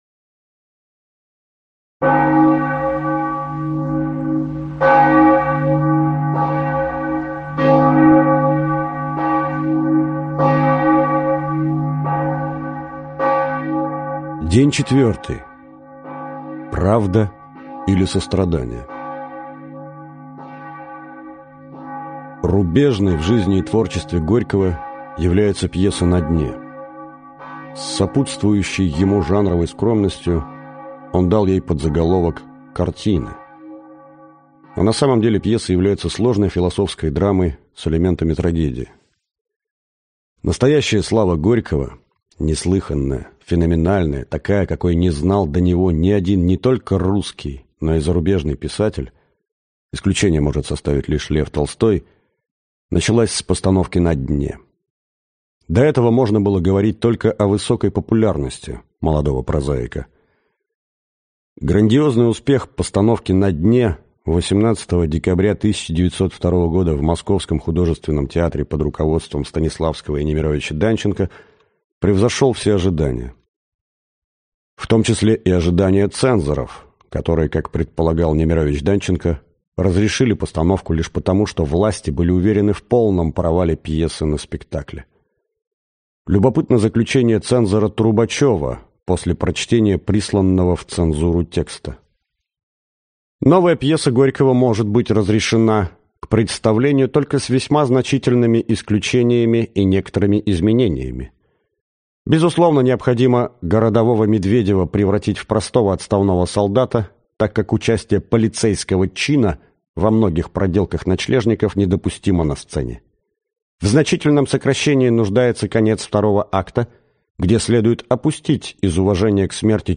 Аудиокнига Страсти по Максиму. Горький: девять дней после смерти | Библиотека аудиокниг